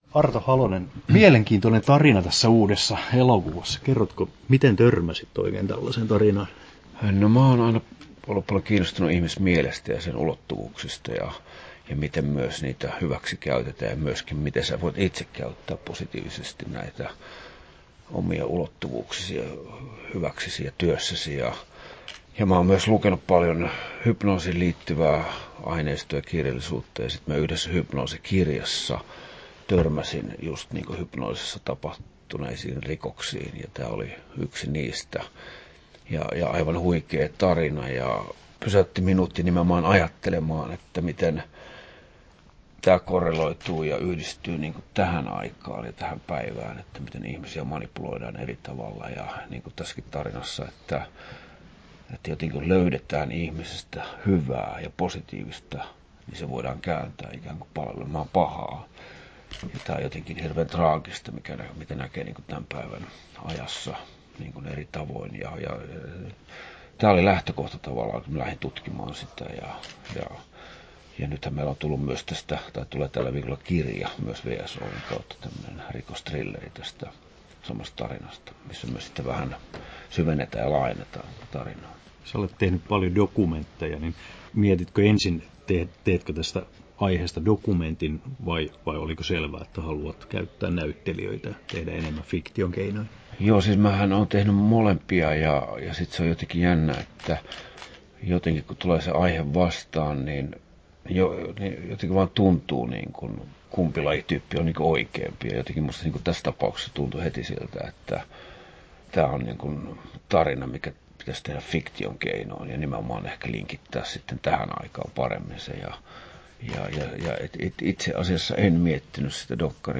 Haastattelussa
11'38" Tallennettu: 14.03.2018, Turku Toimittaja